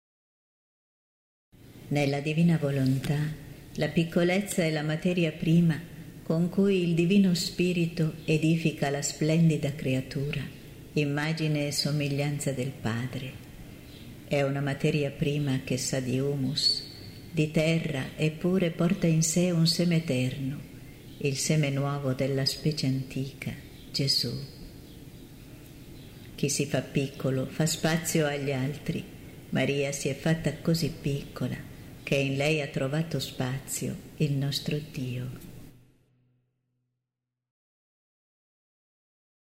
preghiera mp3 –